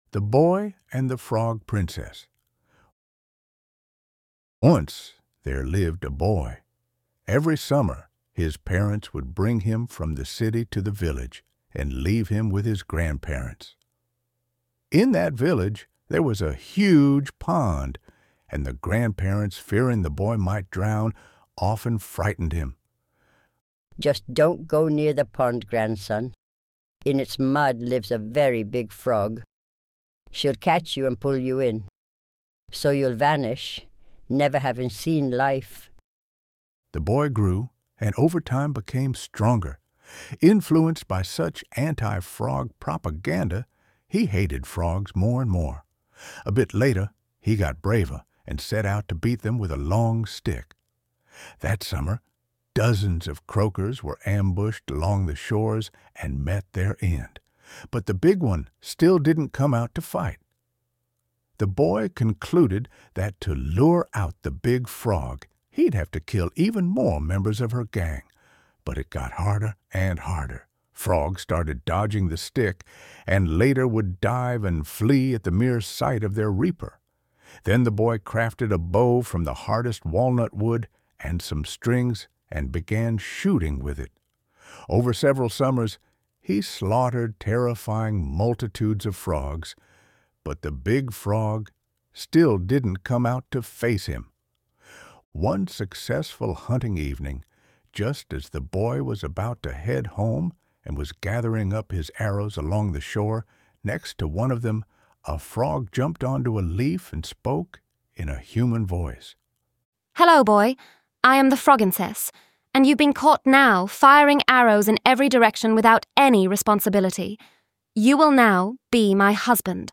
Human tales, translated from Lithuanian, with illustrations and music made by thinking machines.